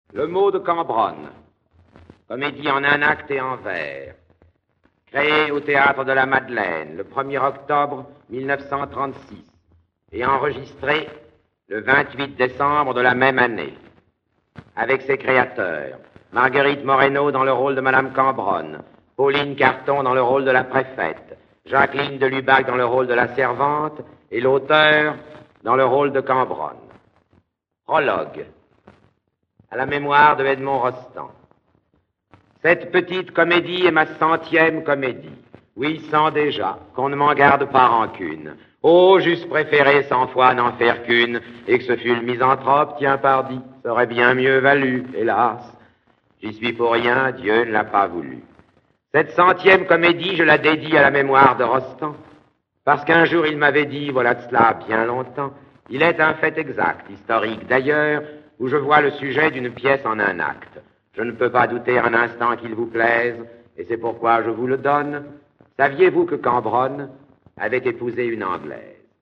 Comédie en 1 acte, interprétée par Marguerite Moreno (la générale Cambronne), Pauline Carton (la préfète), Jacqueline Delubac (la servante), et Sacha Guitry (le général Cambronne). Enregistrement original (extraits)